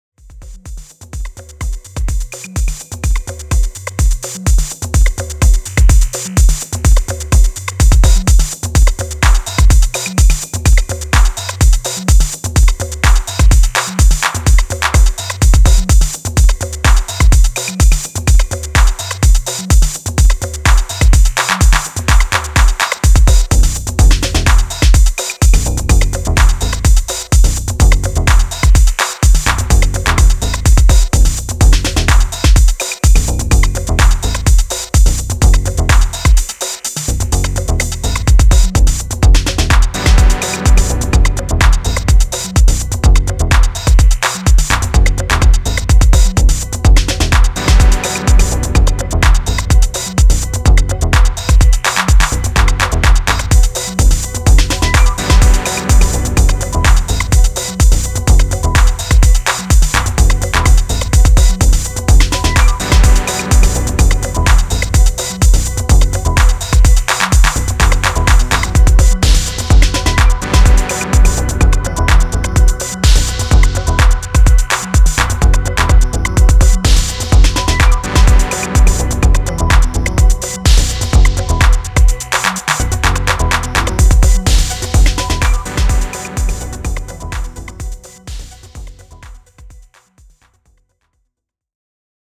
ソリッドなディープ・ハウス群を展開しています。